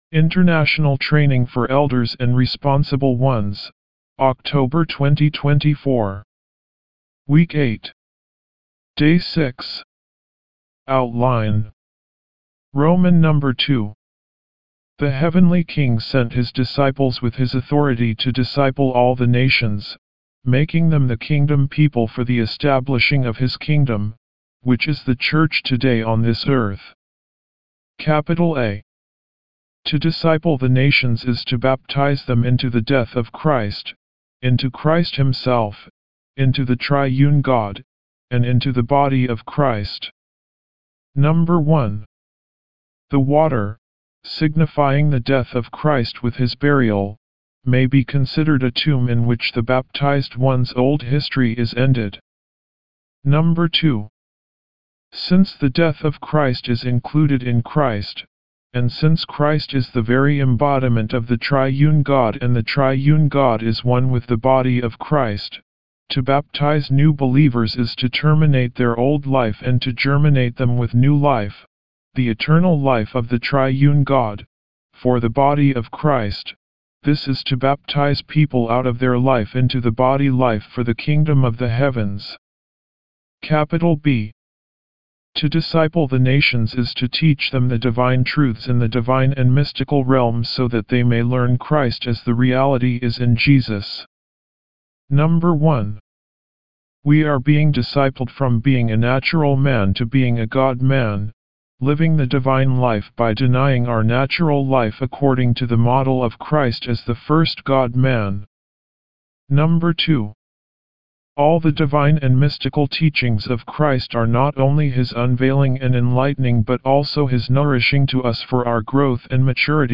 D6 English Rcite：